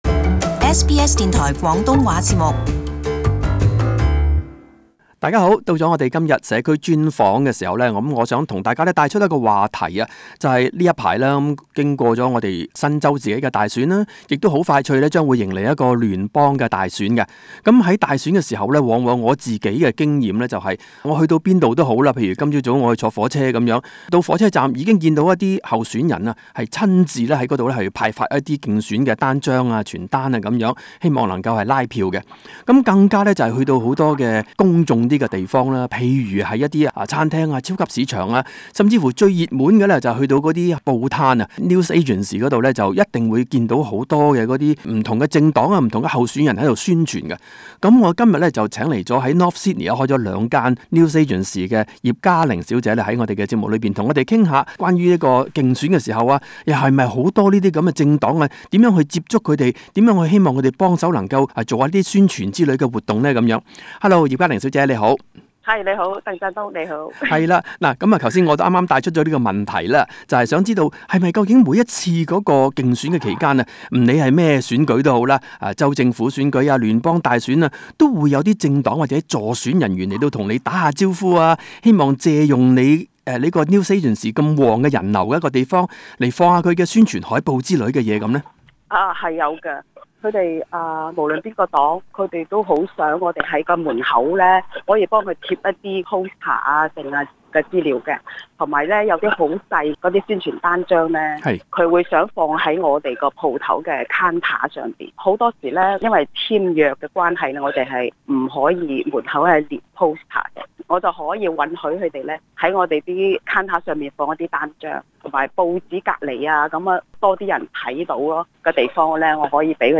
【社區專訪】競選宣傳花樣多，報攤慣成搶手貨